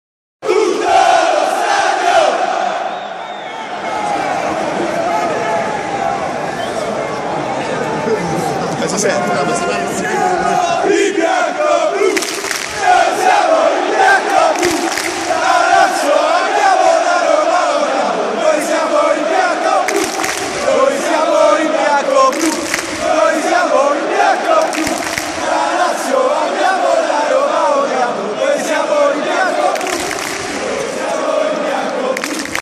Qui troverete tutti i cori che si cantano allo Stadio.